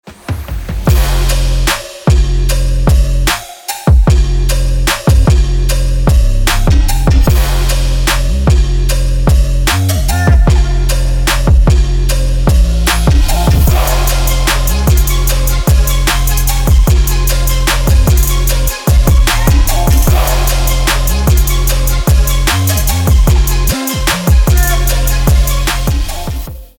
• Качество: 320, Stereo
Electronic
без слов
Trap
club
Bass
Tribal Trap